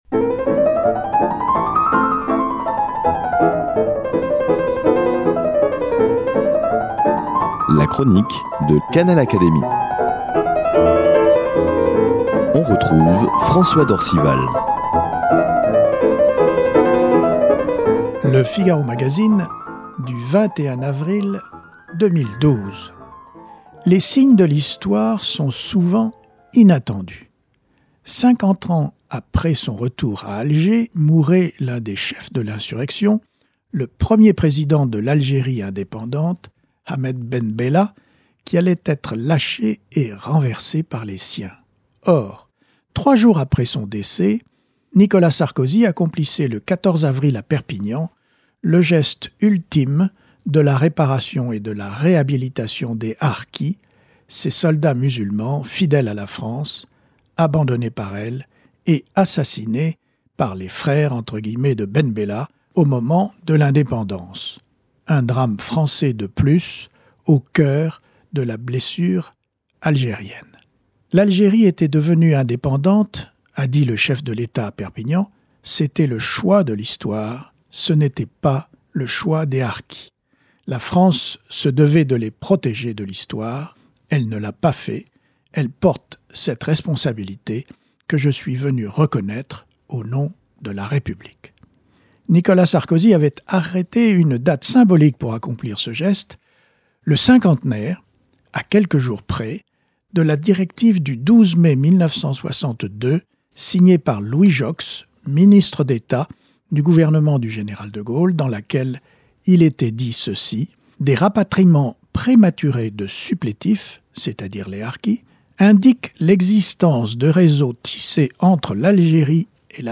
Toujours d’actualité... la chronique de François d’Orcival de l’Académie des sciences morales et politiques